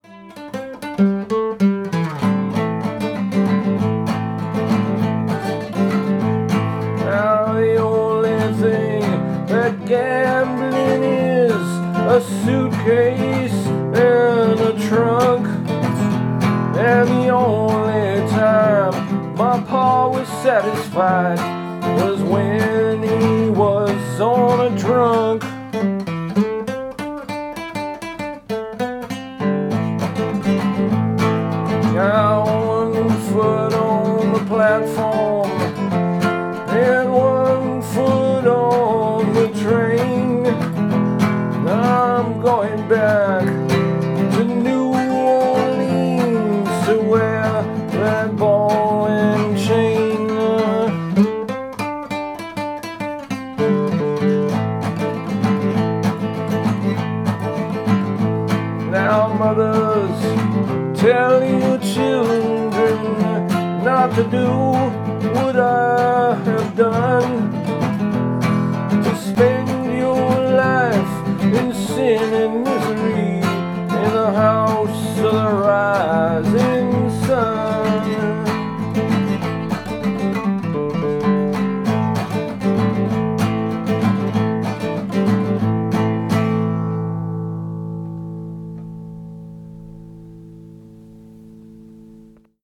Genre: Trad Rock.